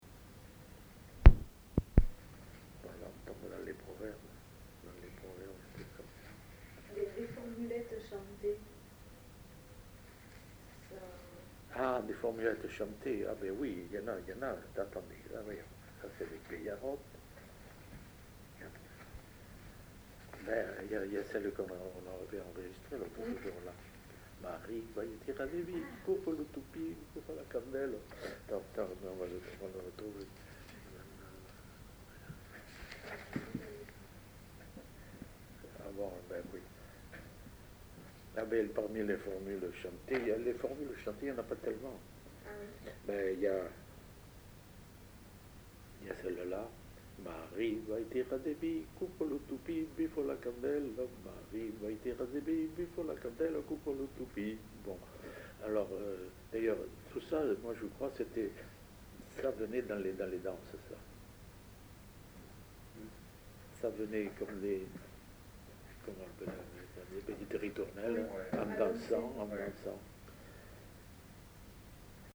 Aire culturelle : Rouergue
Genre : chant
Effectif : 1
Type de voix : voix d'homme
Production du son : chanté
Classification : danses